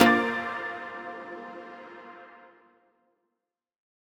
menu-play-click.mp3